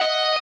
guitar_016.ogg